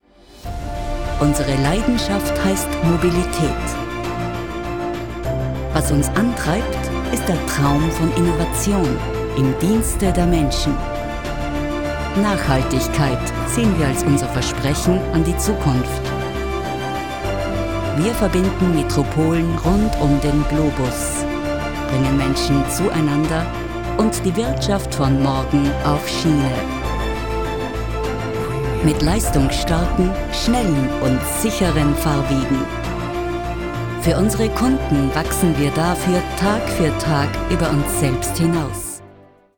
Die Stimme ist bekannt aus zahlreichen Werbeproduktionen, sehr variantenreich von seriös, entspannt erzählerisch bis frech, sinnlich, warm.
Sprechprobe: Industrie (Muttersprache):
She can sound deep and trustworthy but also very energetic.